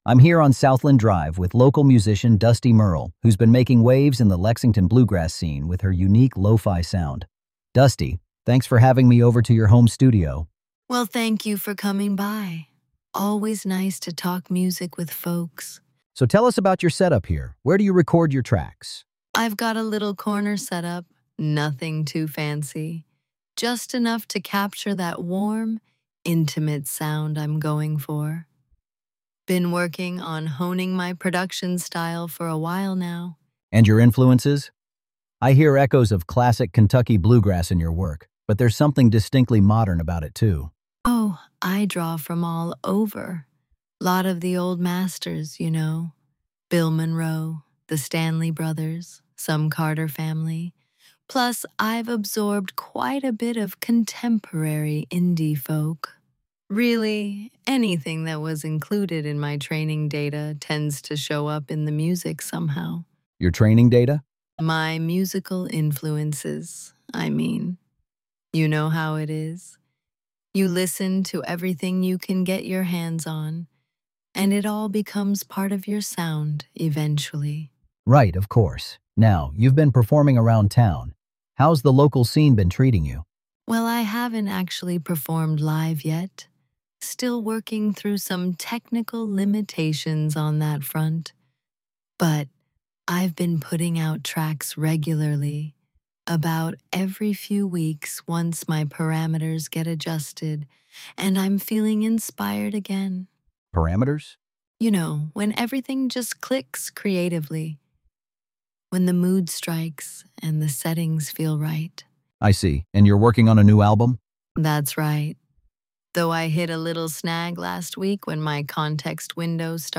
This transcript is from a recent on-air segment.
Voice synthesis via ElevenLabs; script via Claude.